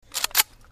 Перезарядка оружия (Reload gun)
Отличного качества, без посторонних шумов.
114_reload-gun.mp3